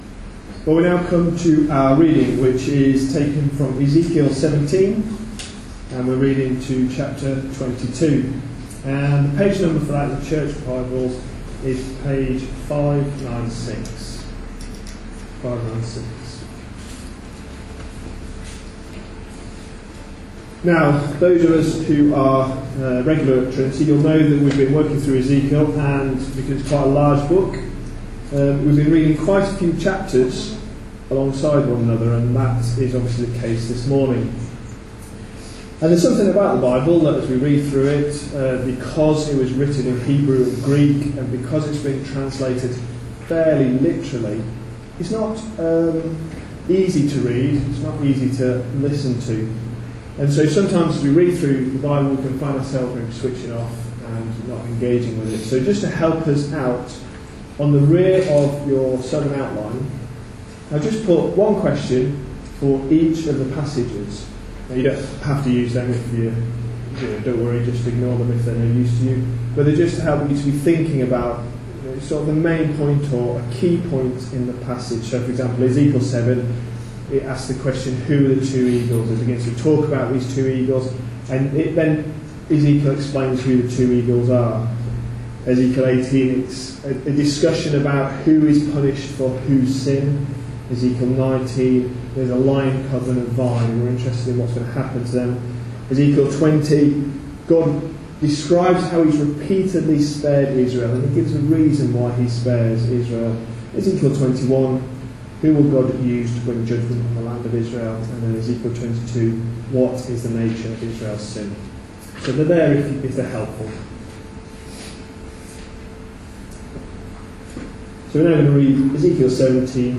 A sermon preached on 6th July, 2014, as part of our Ezekiel series.